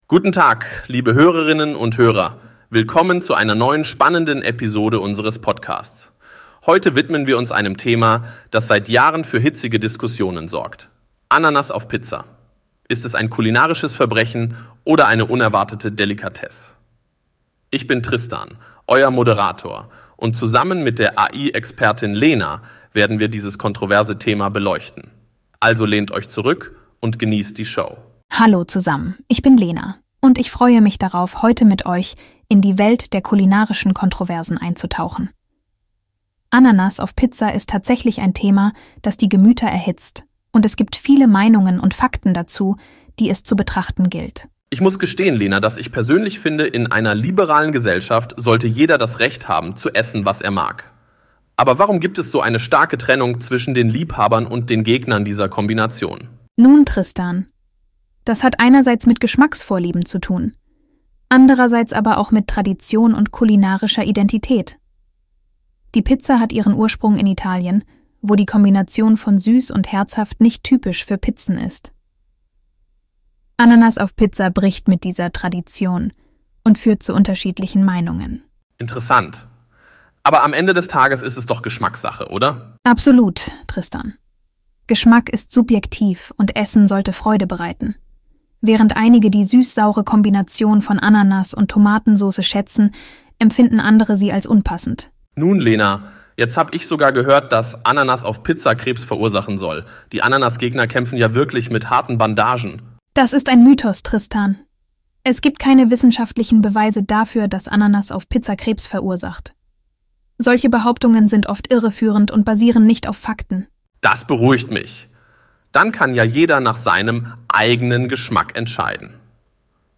Lauscht der lebhaften Diskussion und genießt die Show – nur hier in unserem Podcast!